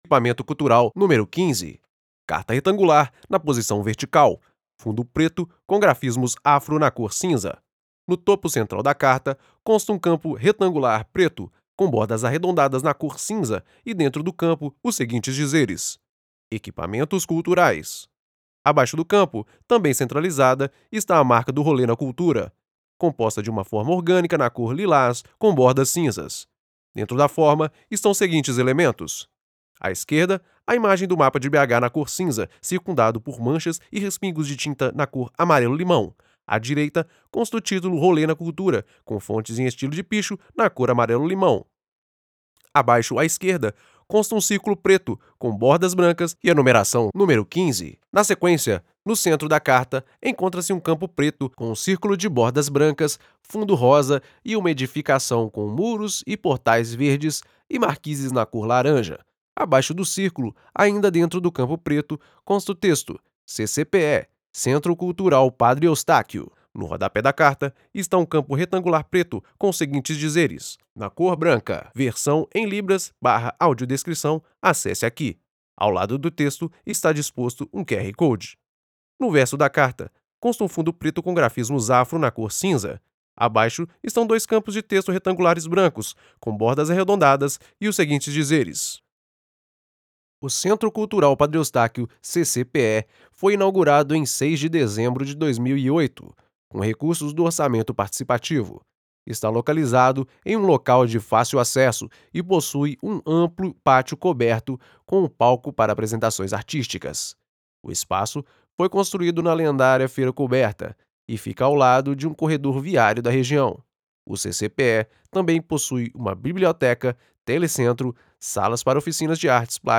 Audiodescrição: